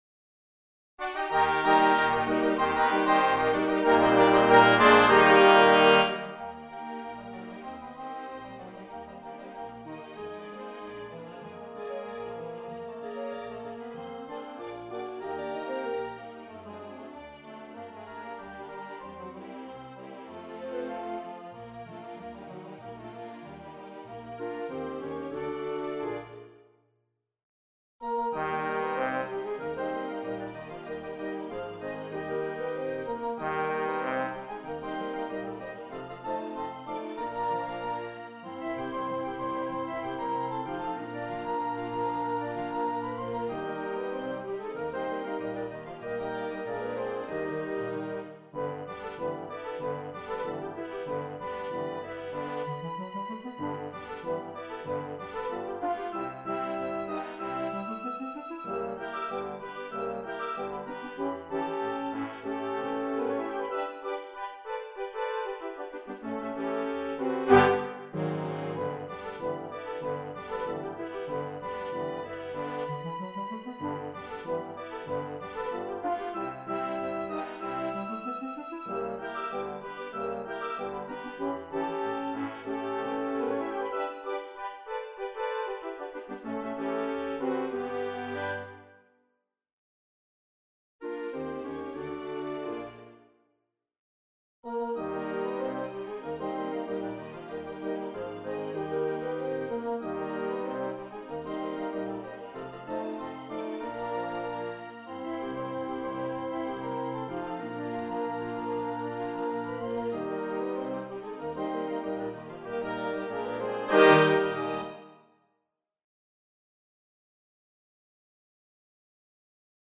CON and KID. Tina joins reprise